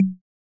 back-button-hover.wav